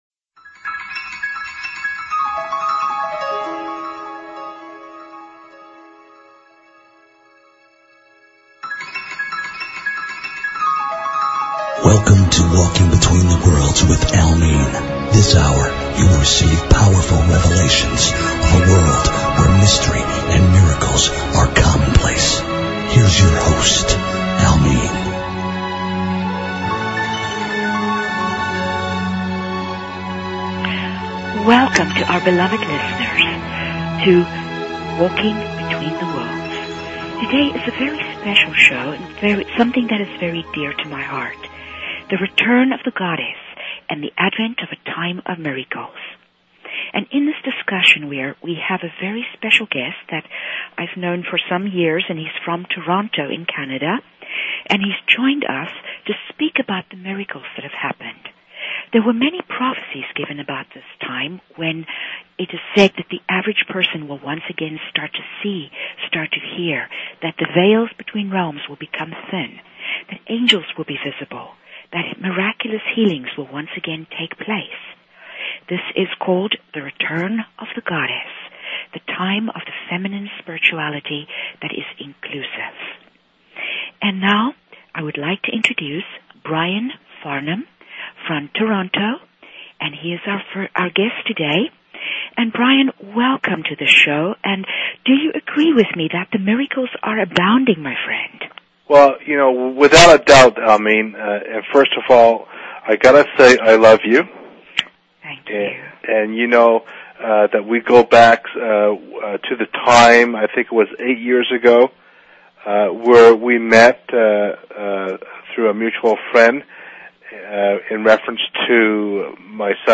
These interviews are powerful enough to change your life!